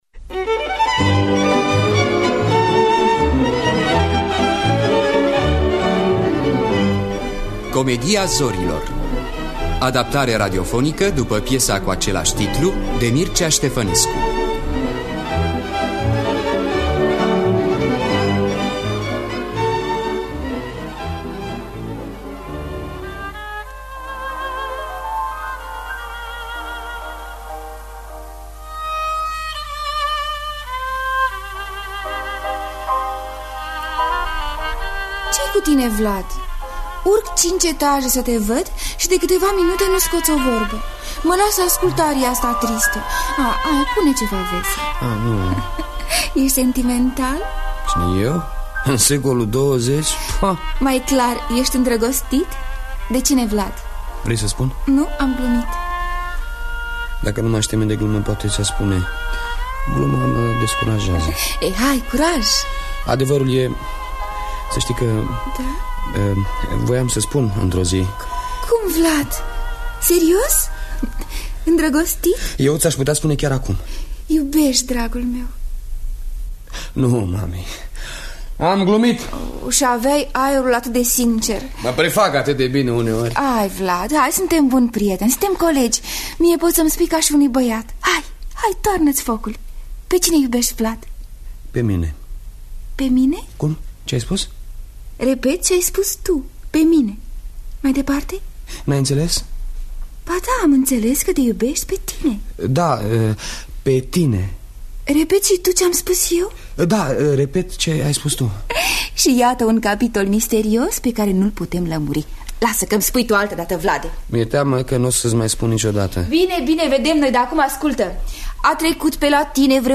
Comedia zorilor de Mircea Ștefănescu – Teatru Radiofonic Online
În distribuţie: Valeria Seciu, Emil Hossu, Florian Pitiş, George Bănică.